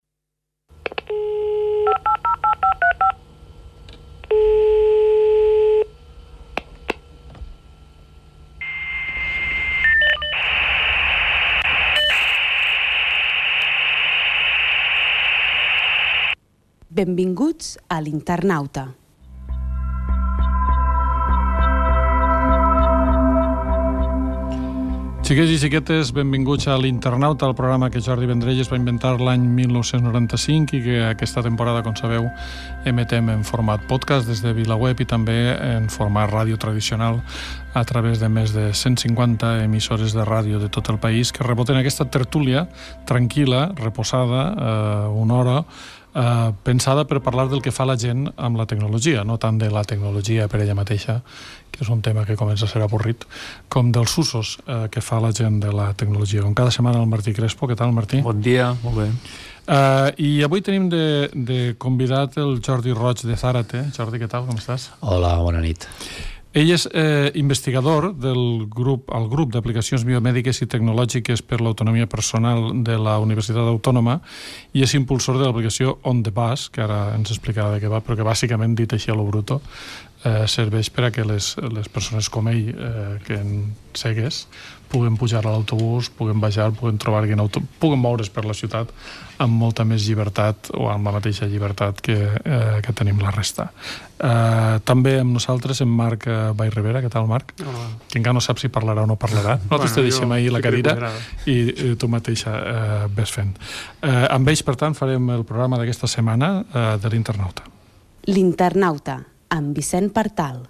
Inici del programa, presentació de l'invitat
Divulgació